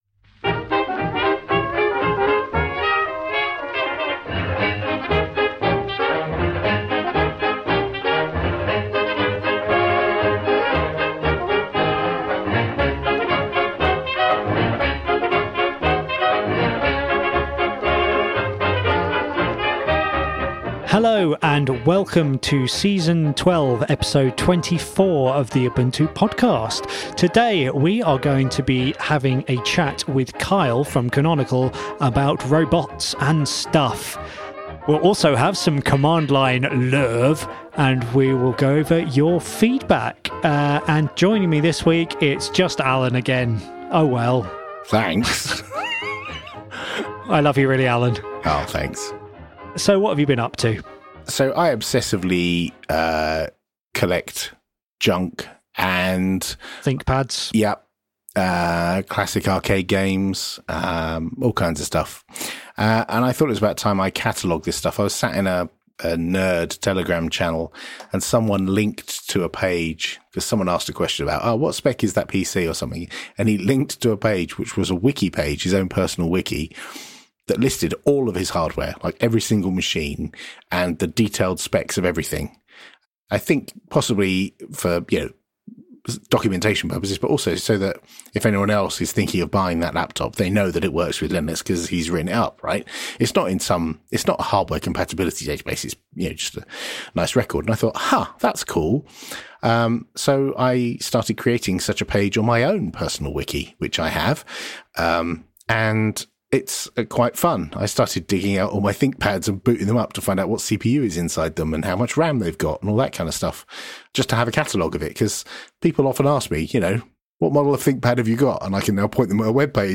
This week we’ve been cataloging hardware (mostly crusty Thinkpads). We interview